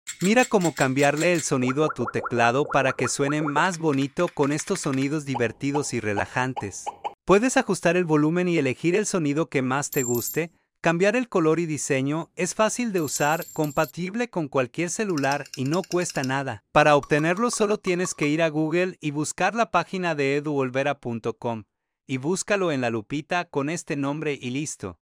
Sonidos bonitos para tu teclado sound effects free download